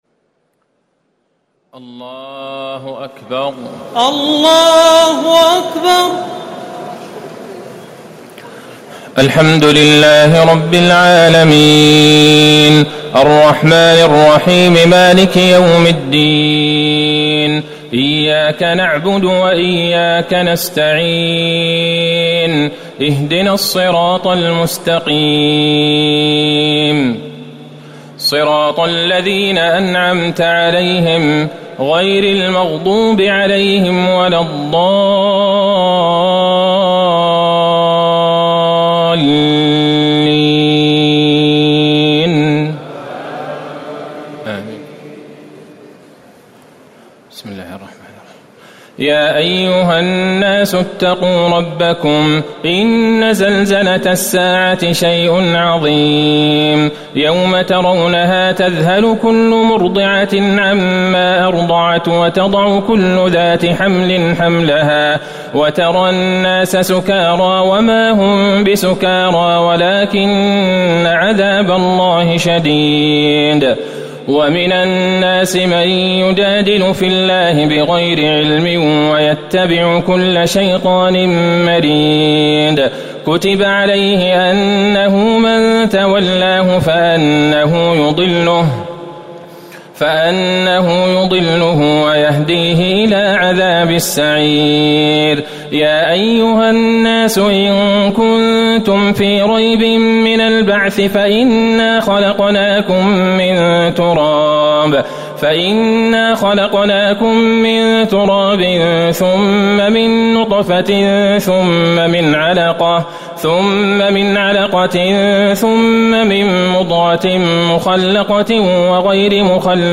تراويح الليلة السادسة عشر رمضان 1439هـ سورة الحج كاملة Taraweeh 16 st night Ramadan 1439H from Surah Al-Hajj > تراويح الحرم النبوي عام 1439 🕌 > التراويح - تلاوات الحرمين